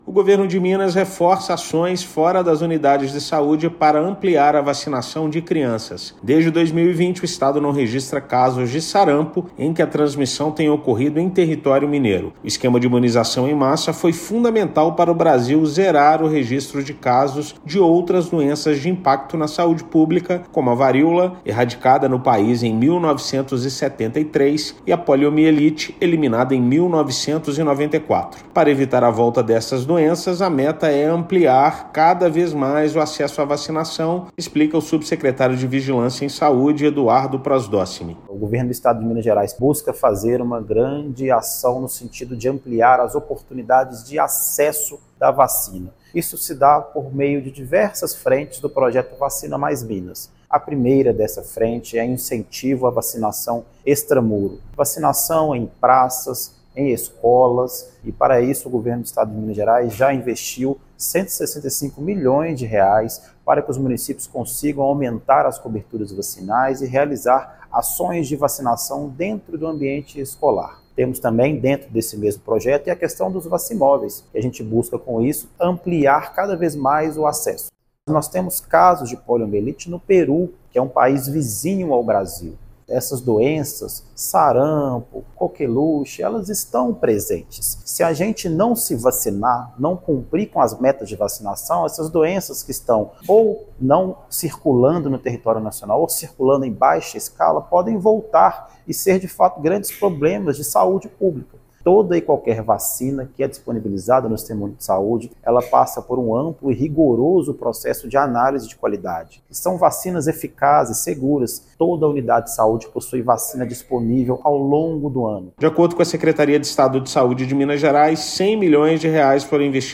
Com o Programa Vacina Mais, Minas, Estado reforça iniciativas como o vacimóvel para facilitar o acesso à imunização nos municípios. Ouça matéria de rádio.